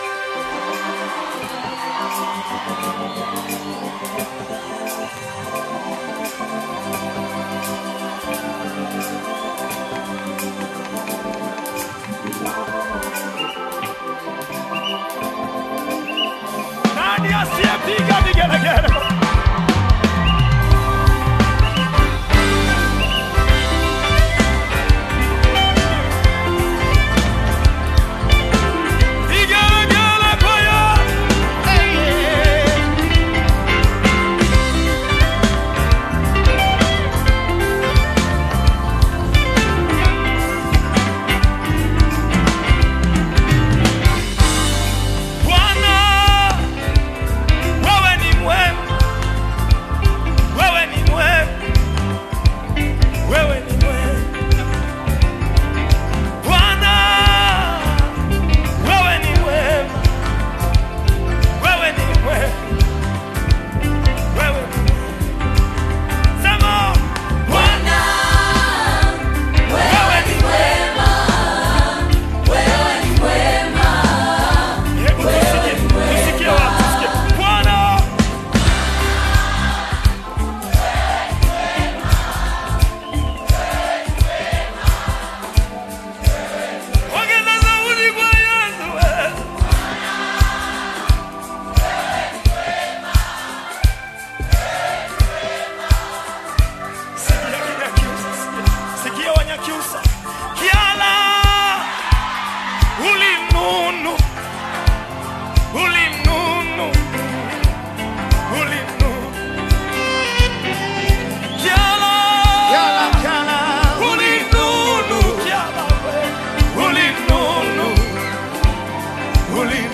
Tanzanian gospel singer
worship song
African Music